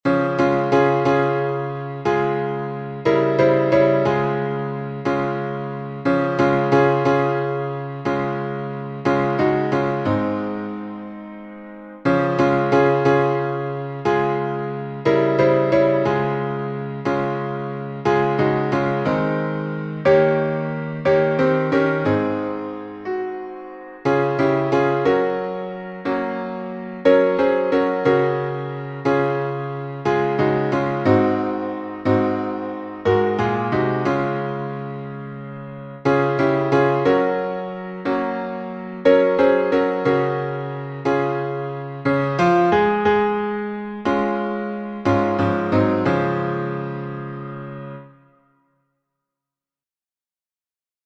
Words by Thomas O. Chisholm (1866-1960)Tune: RONDINELLA by William J. Kirkpatrick (1838-1921)Key signature: D flat major (5 flats)Time signature: 9/8Public Domain1.